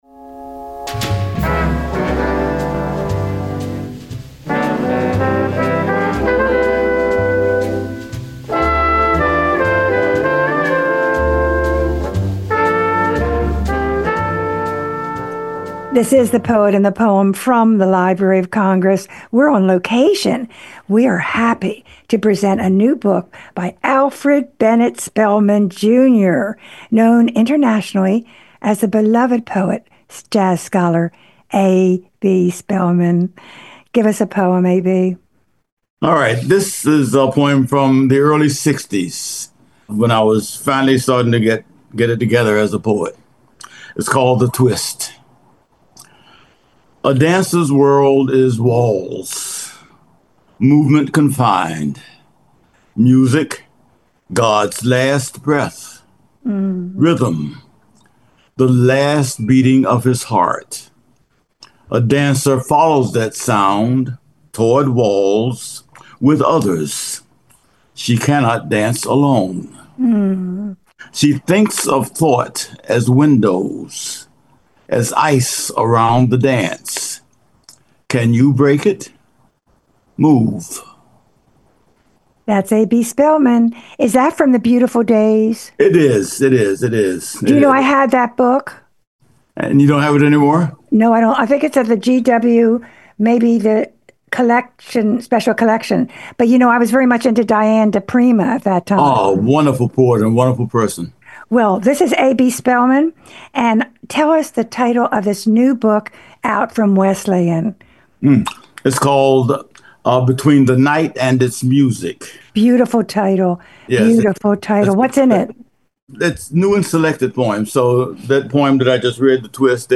She hosted and produced the long-standing poetry program THE POET AND THE POEM on WPFW-FM for 20 years in Washington DC.